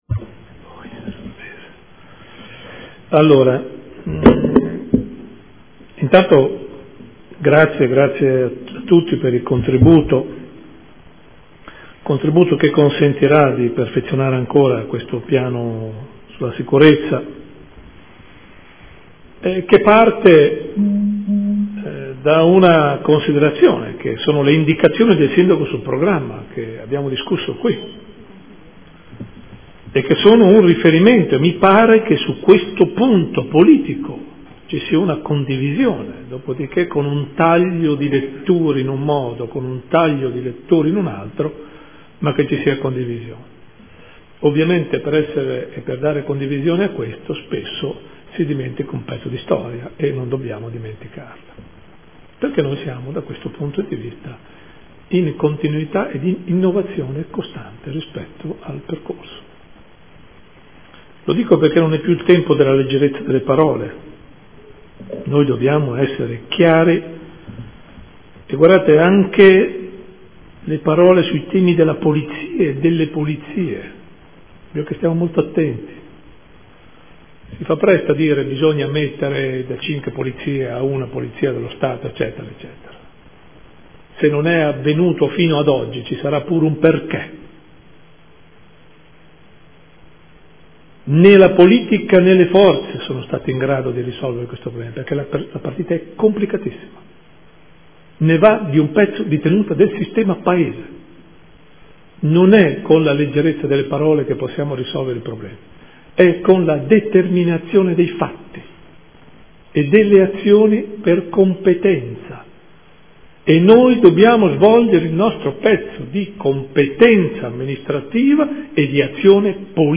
Seduta del 9/10/2014 Dibattito Sicurezza.